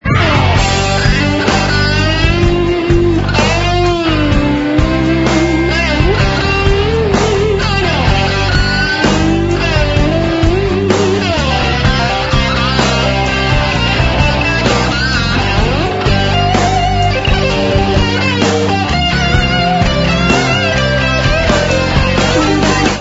Ils sont au format mp3, 32 kbps, 22 KHz, mono.